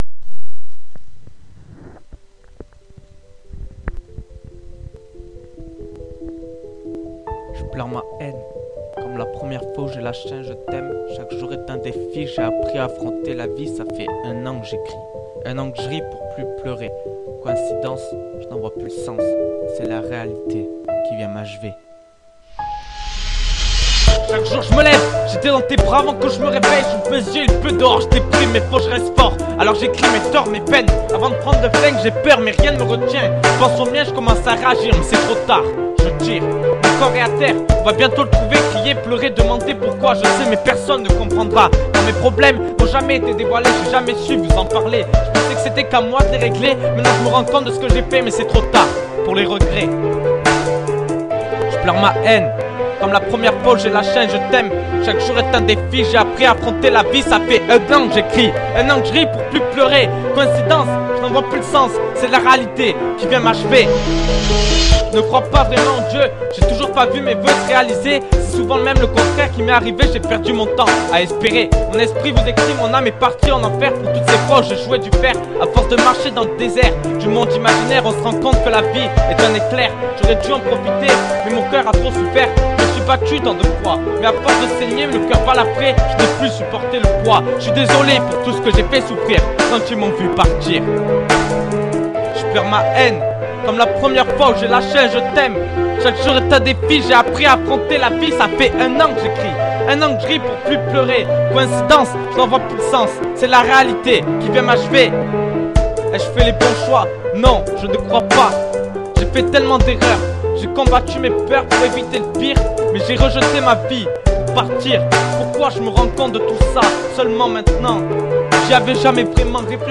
Rappeur qui débute attend vos avis ...